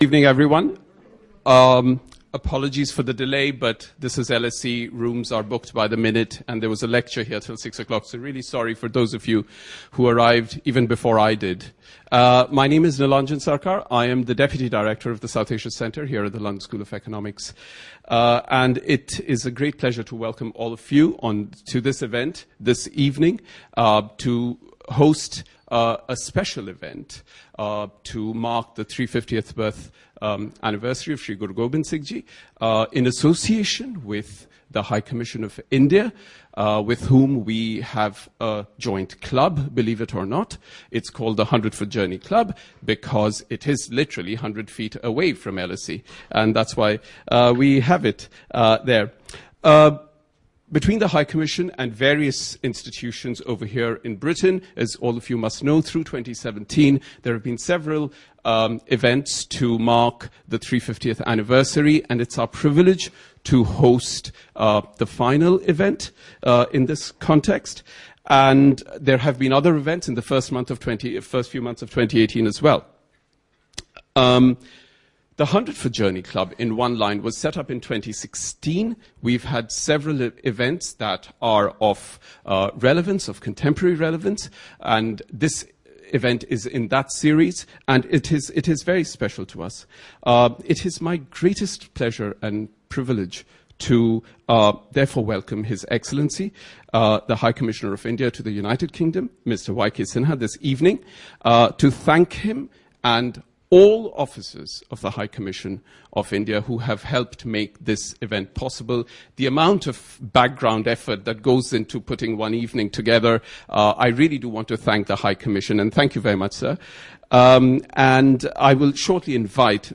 Listen to the podcast of the event: